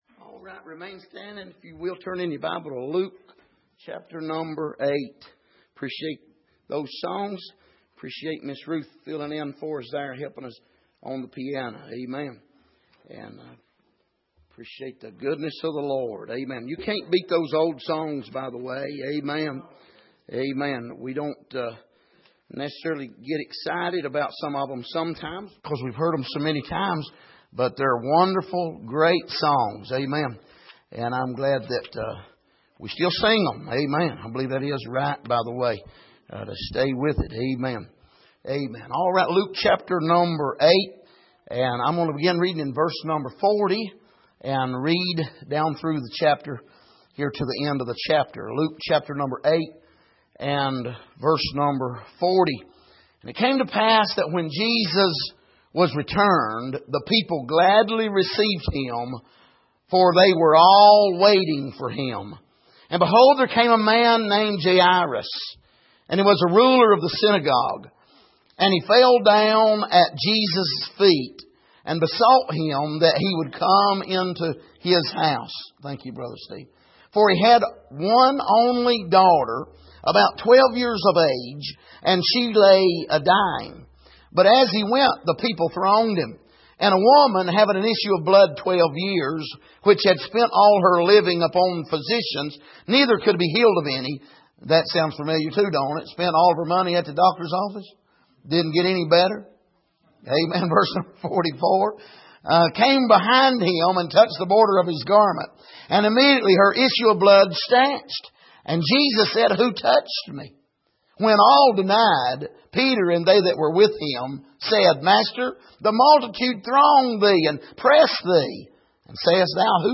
Passage: Luke 8:40-56 Service: Midweek